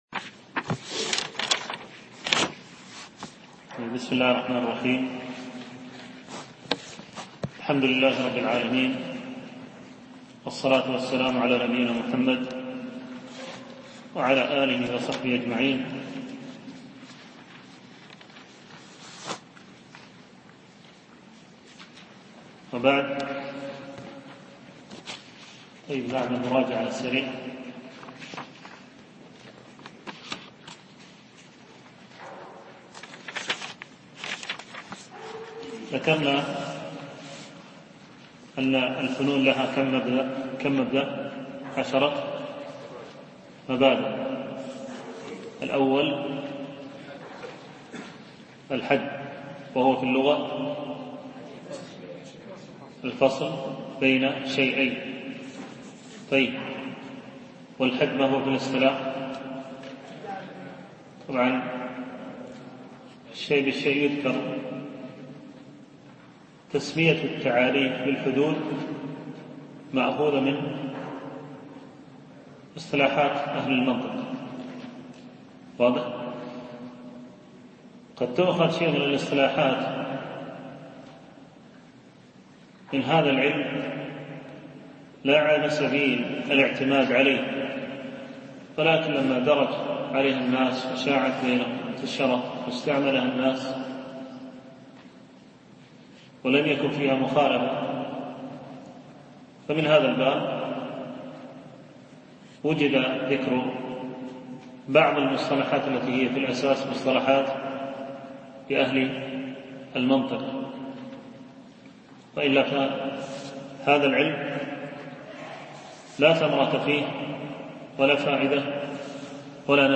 شرح رسالة مختصر أصول الفقه (للعلامة السعدي) ـ الدرس الثاني